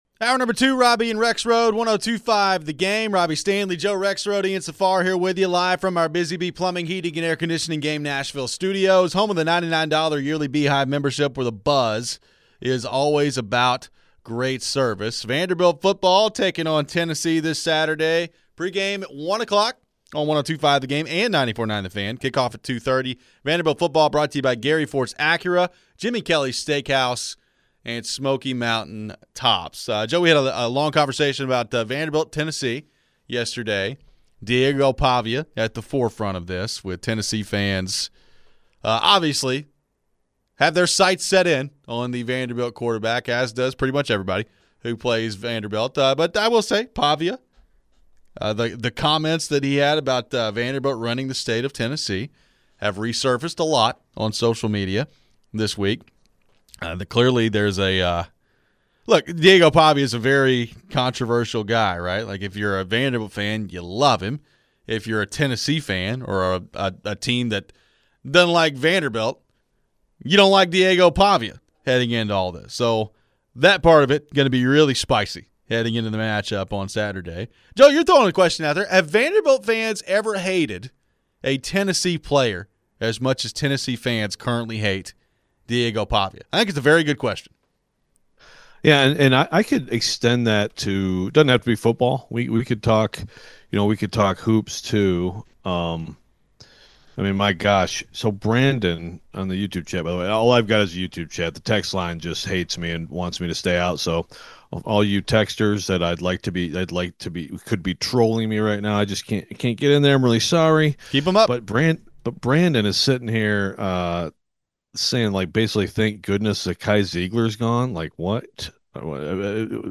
We head to your phones.